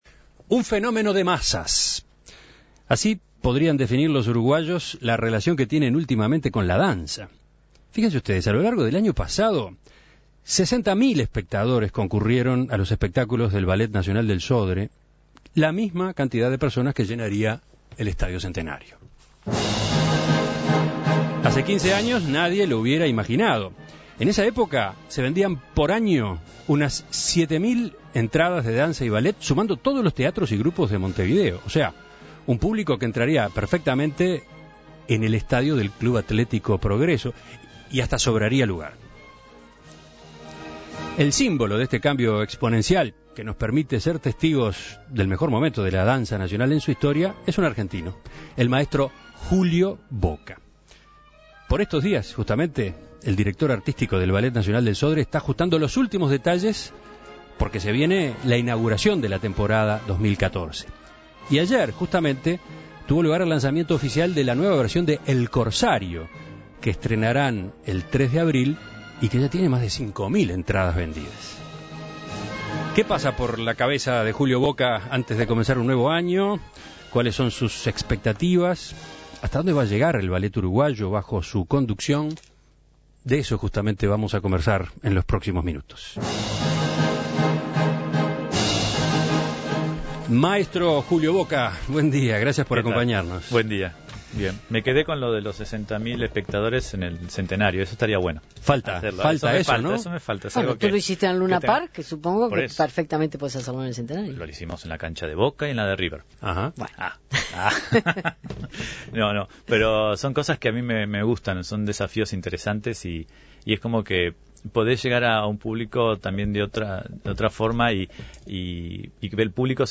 A pocas semanas de que comience oficialmente la nueva temporada, En Perspectiva habló con el argentino sobre la actualidad del Ballet y sobre sus proyectos para los próximos años.